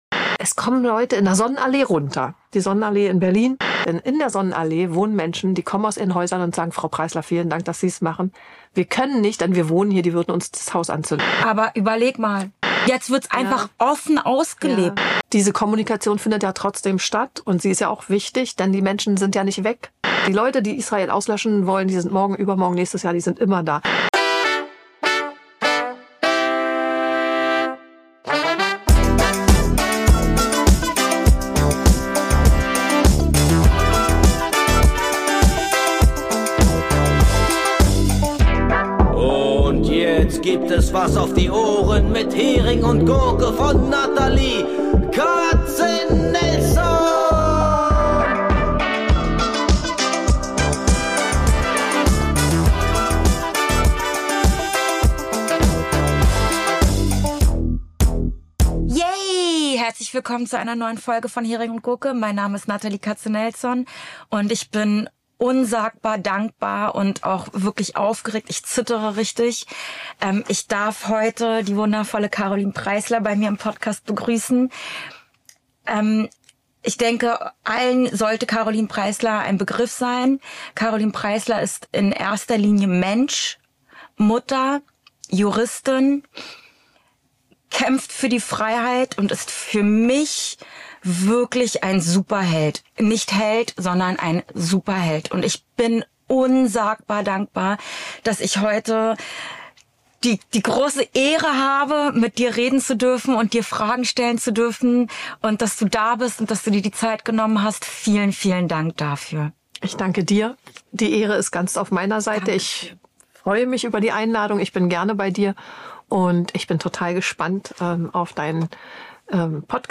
Ein Austausch über Perspektiven, Verantwortung und darüber, warum Menschlichkeit keine Meinung ist, sondern eine Entscheidung.